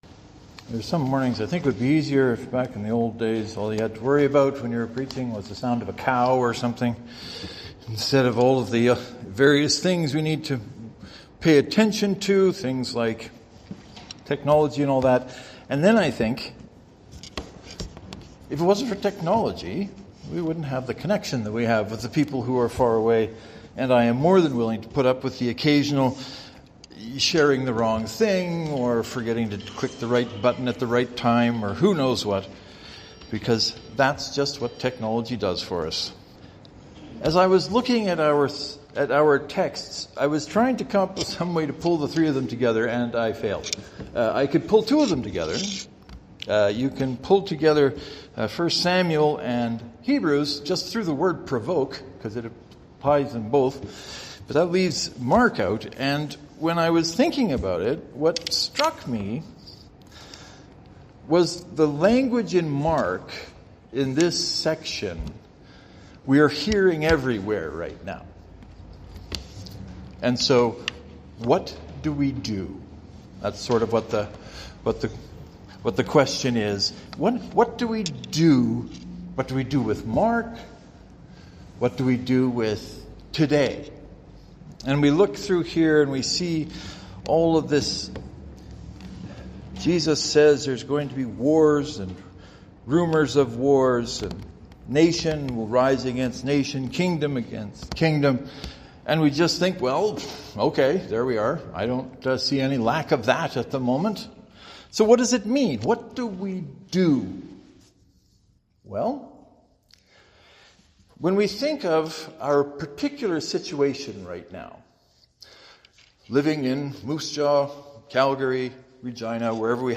Well, I have a sermon that might help you a bit with it, it helps me.
By the way, there are some sounds, clicks and the like, which I couldn’t get out of the recording so if you hear them, there is nothing wrong with your system or your ears.
St. Mark’s Presbyterian (to download, right-click and select “Save Link As .